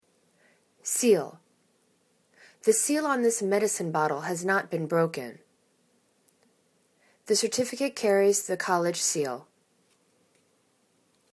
seal  /se:l/ [C]